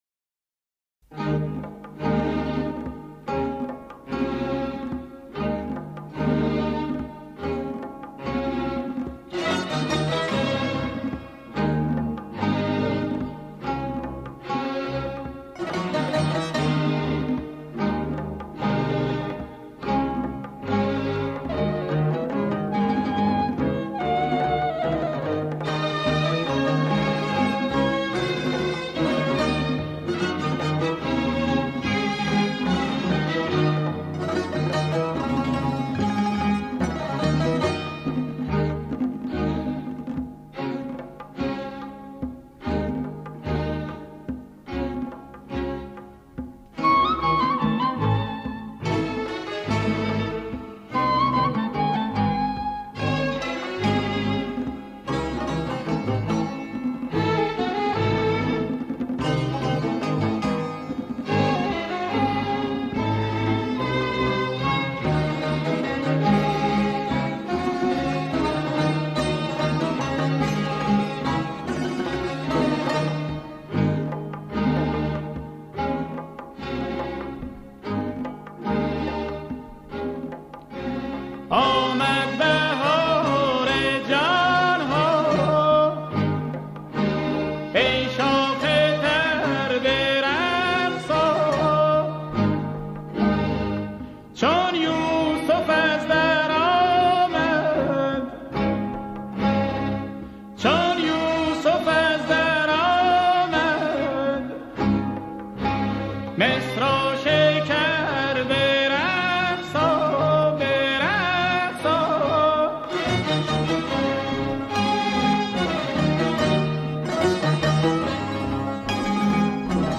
در کنار سازهای ایرانی از سازهای غربی استفاده کرده است